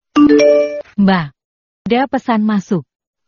Nada notifikasi Mbak ada pesan masuk
Kategori: Nada dering
nada-notifikasi-mbak-ada-pesan-masuk-id-www_tiengdong_com.mp3